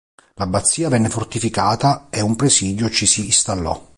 Pronúnciase como (IPA) /preˈsi.djo/